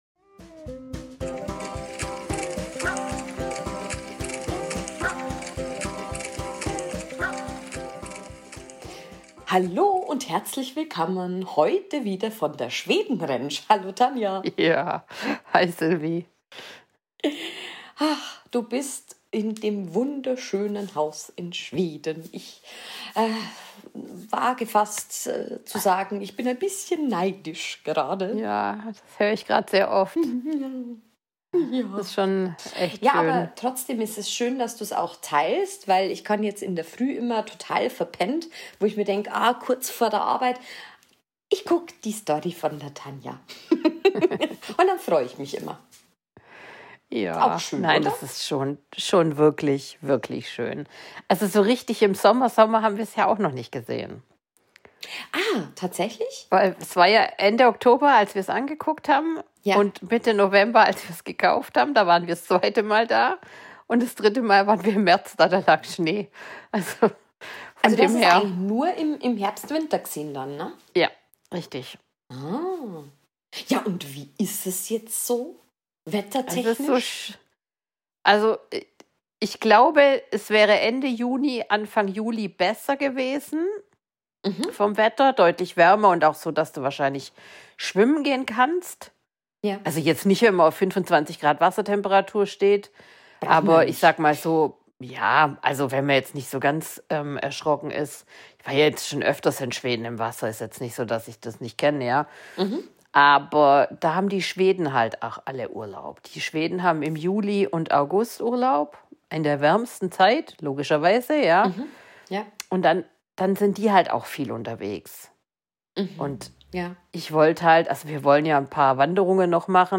Heute wieder live aus Schweden.